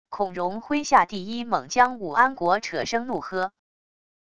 孔融麾下第一猛将武安国扯声怒喝wav音频